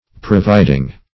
Providing - definition of Providing - synonyms, pronunciation, spelling from Free Dictionary